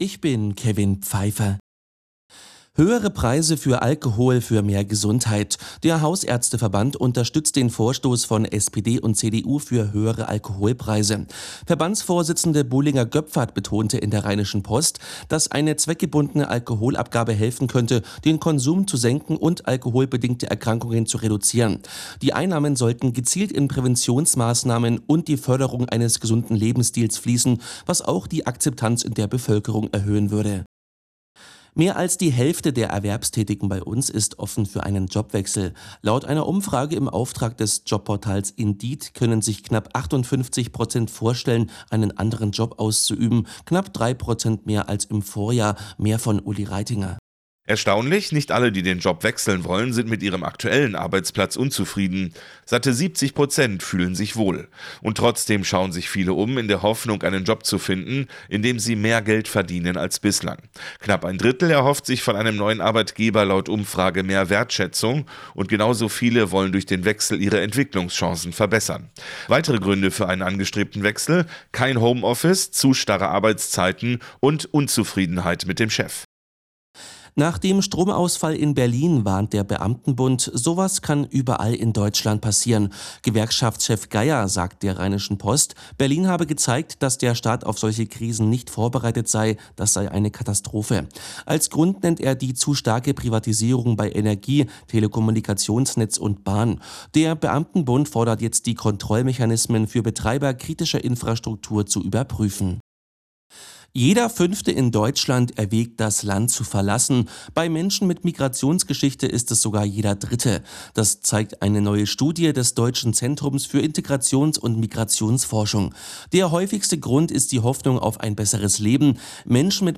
Die Arabella Nachrichten vom Samstag, 10.01.2026 um 12:59 Uhr